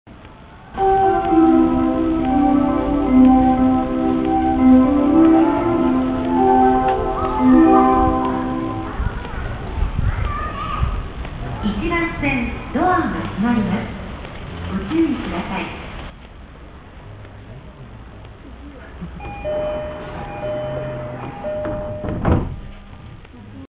なお、足元の点字ブロックやホームとドアの段差などの解決はE531系に準じたものであるが、ドアモーターとドアチャイムの音量は山手線E231系と同じである。
ドア閉|発車メロディ＆発車放送付|
doorclose.mp3